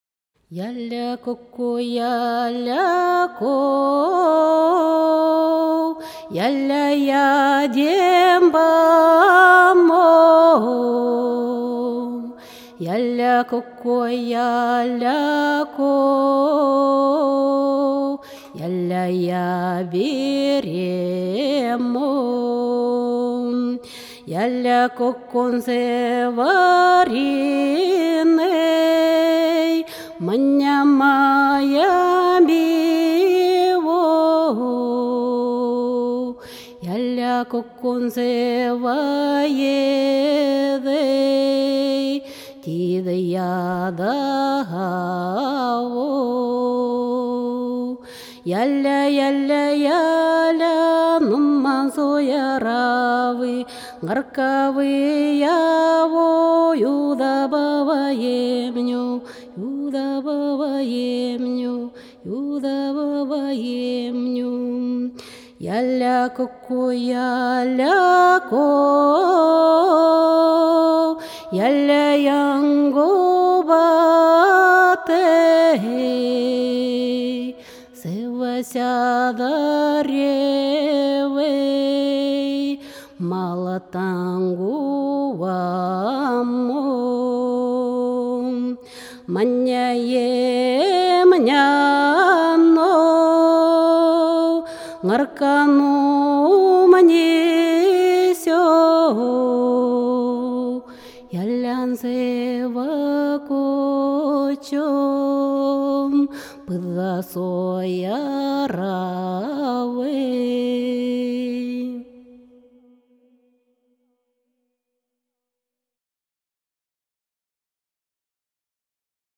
Ненецкая народная песня (закрыта)